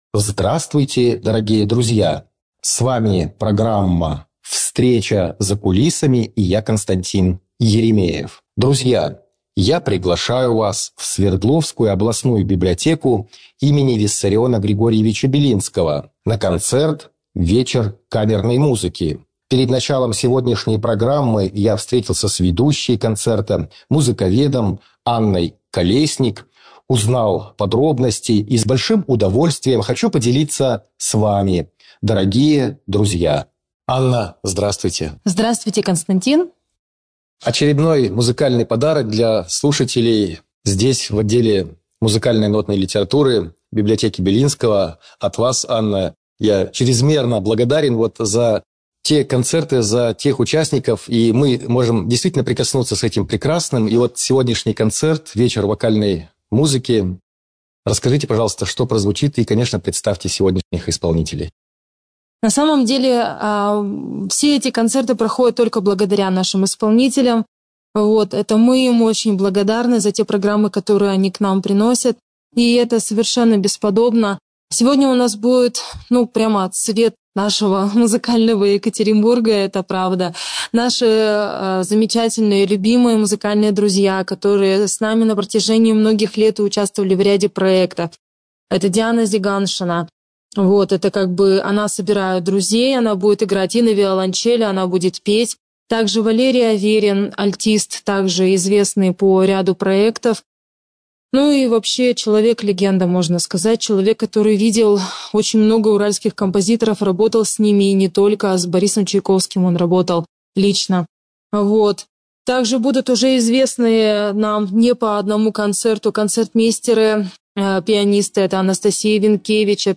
Библиотека им. Белинского. Бард-встреча "Гитарная пристань"